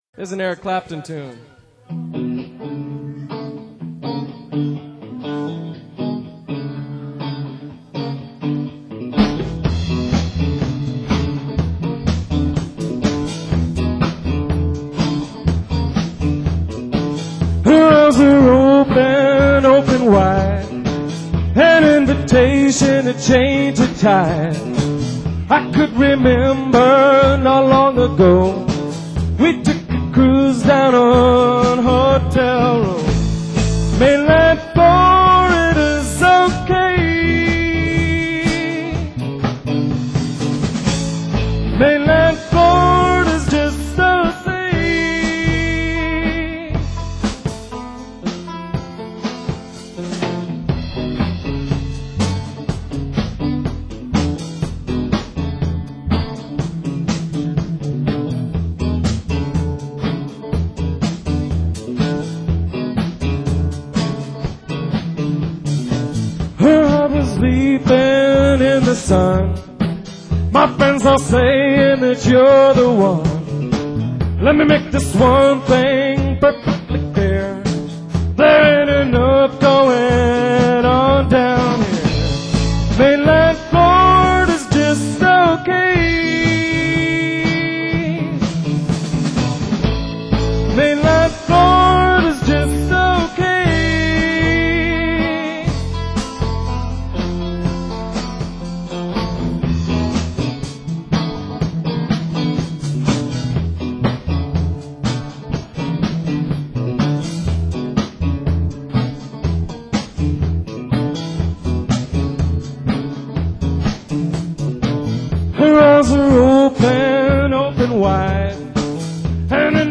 drums
guitar
bass